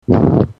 wet fart